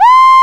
VOICE C5 F.wav